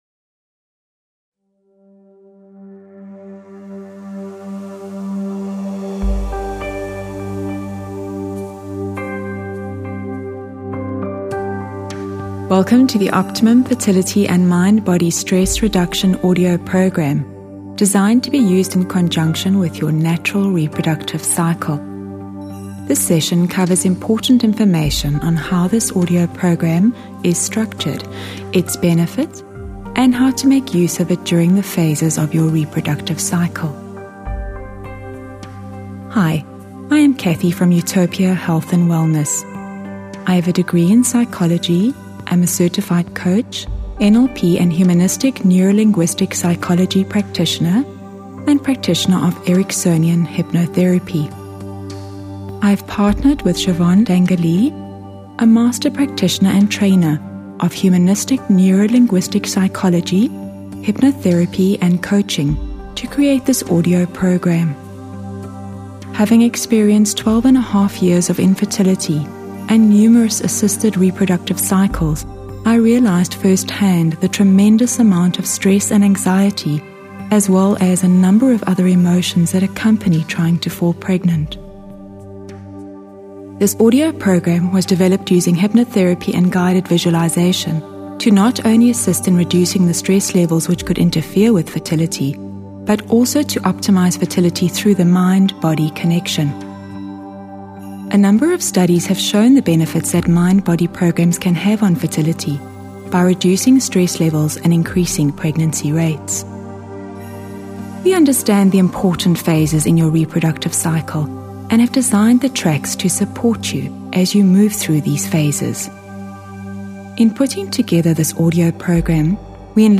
The audio programme makes use of tranquil music, enhanced with audio technologies, specifically designed to facilitate optimal states of receptivity and relaxation.